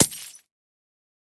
Minecraft Version Minecraft Version snapshot Latest Release | Latest Snapshot snapshot / assets / minecraft / sounds / item / ominous_bottle / dispose.ogg Compare With Compare With Latest Release | Latest Snapshot